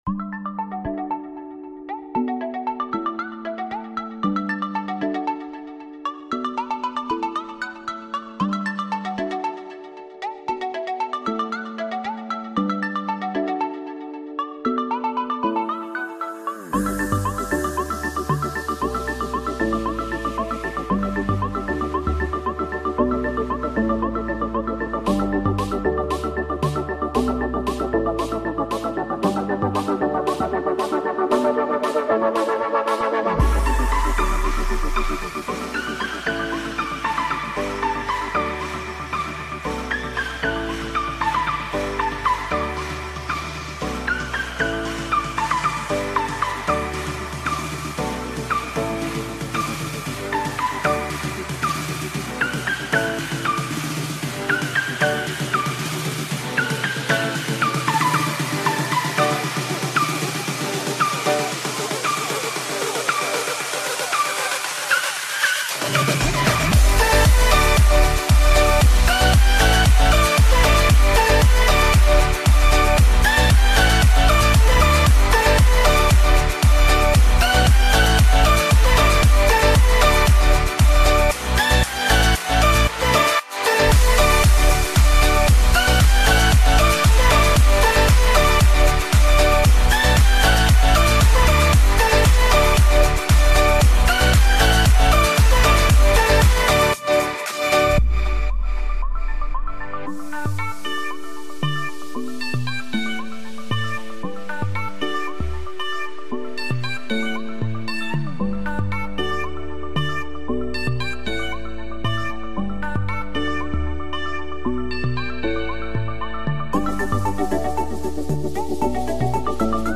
Hehe sound effects free download